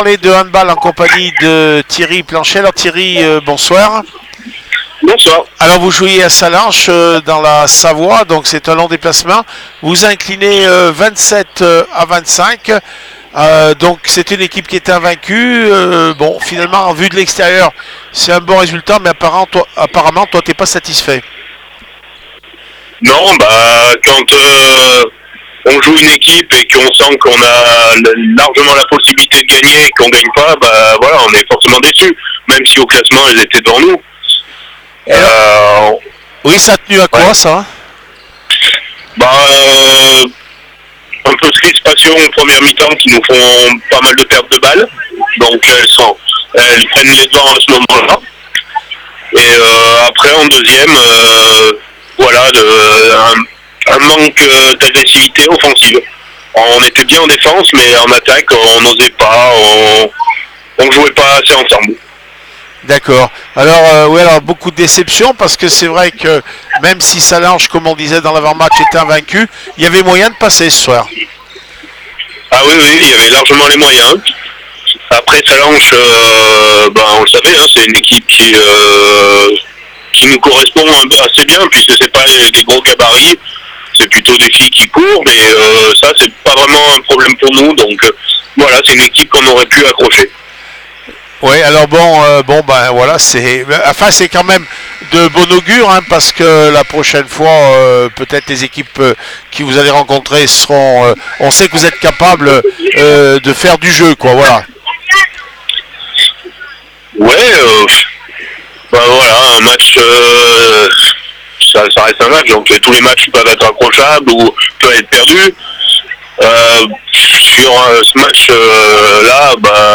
REACTION APRES MATCH